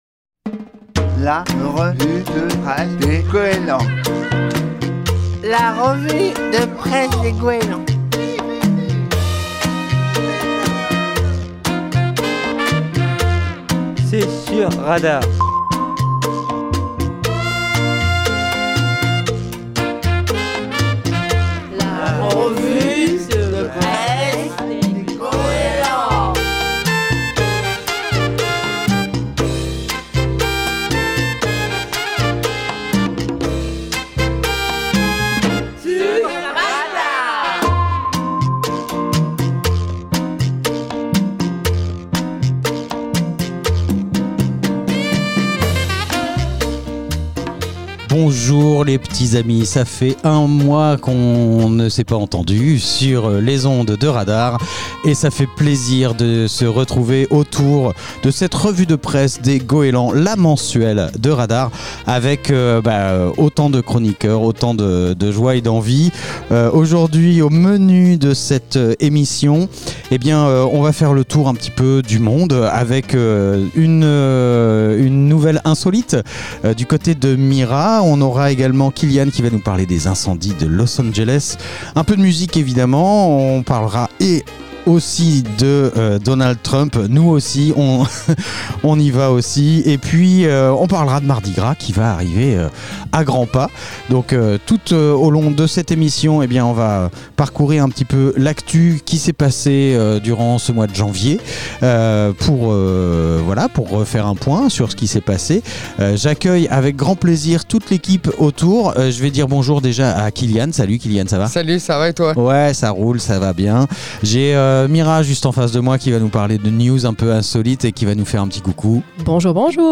Les usagers du foyer d'activités des Goélands de Fécamp font leur revue de presse tous les 2ème mardis de chaque mois sur Radar